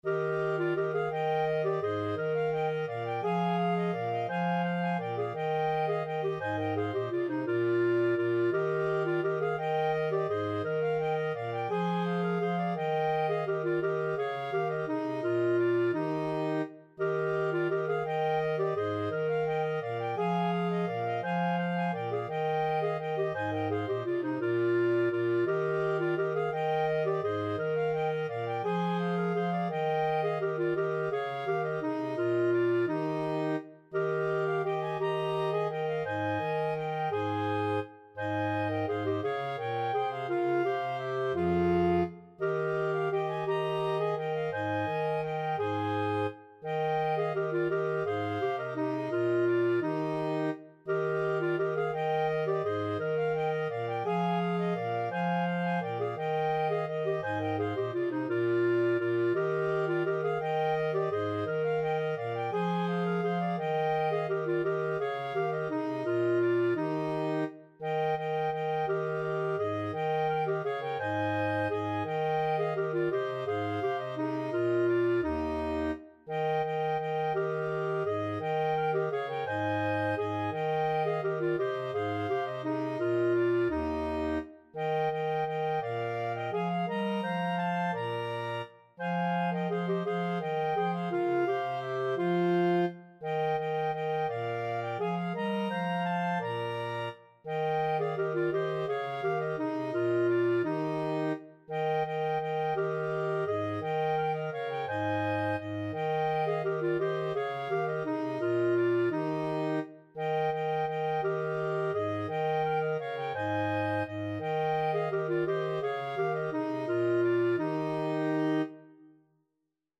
Free Sheet music for Clarinet Quartet
Clarinet 1Clarinet 2Clarinet 3Bass Clarinet
Eb major (Sounding Pitch) F major (Clarinet in Bb) (View more Eb major Music for Clarinet Quartet )
3/2 (View more 3/2 Music)
Classical (View more Classical Clarinet Quartet Music)